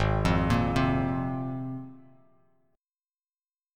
G#6add9 chord